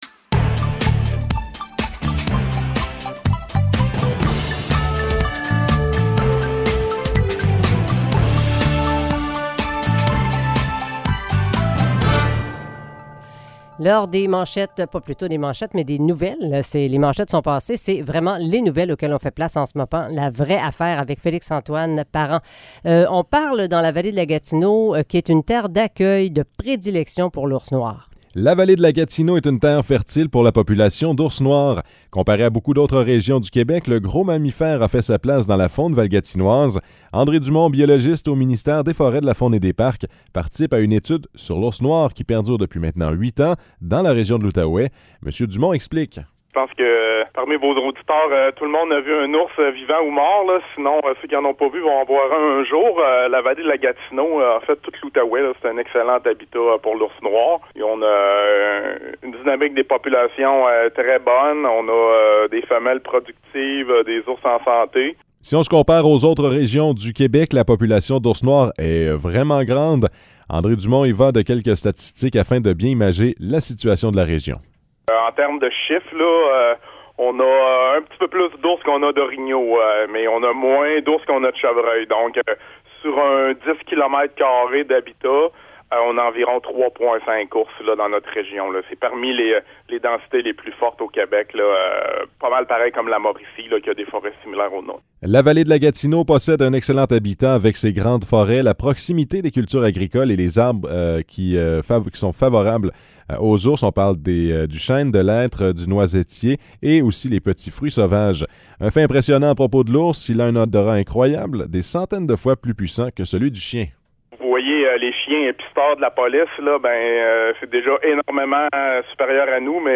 Nouvelles locales - 14 juillet 2022 - 10 h